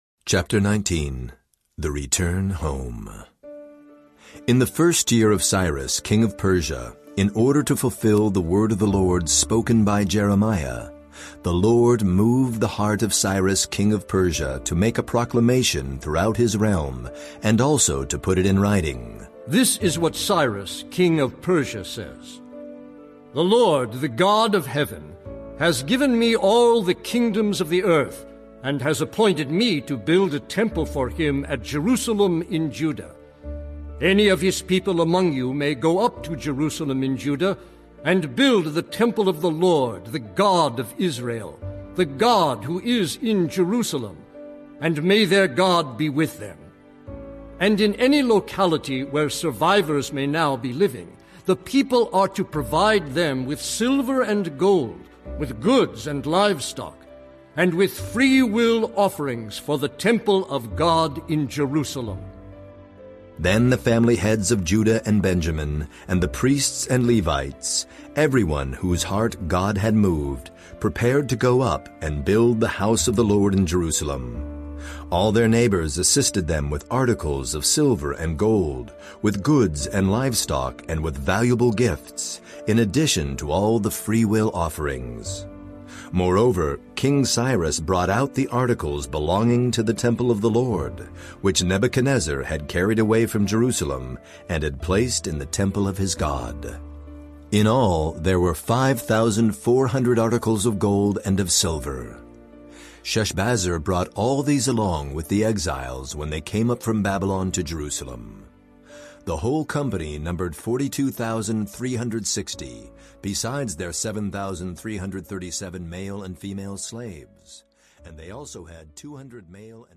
The Story reveals the unfolding, grand narrative of the Scriptures. Using portions of the clear, accessible text of the NIV, this dramatized audio download of Chapter 19 — The Return Home from The Story, NIV allows the stories, poems, and teachings of the Bible to come together in a single, compelling read.
.4 Hrs. – Unabridged